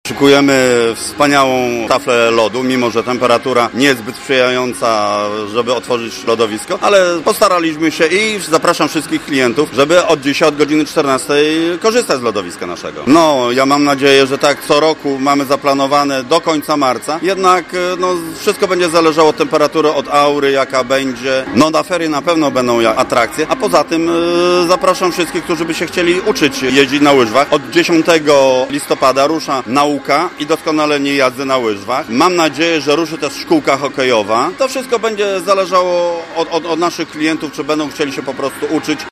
Na obiekcie słychać już muzykę i widać pierwszych entuzjastów jazdy na łyżwach.